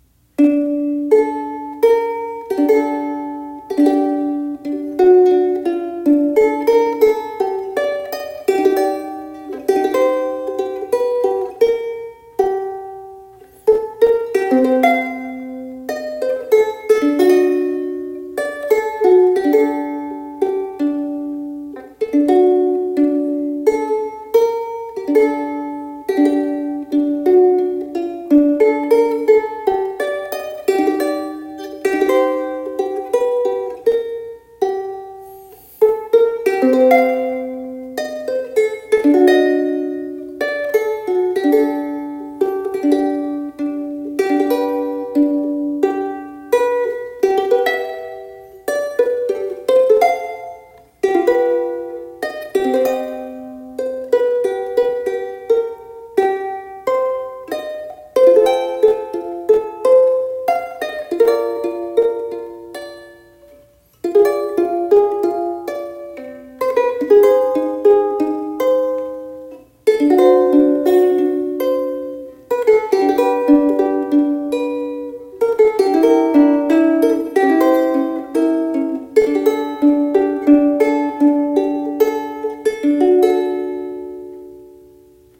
アトリエ一十舎楽器デモ演奏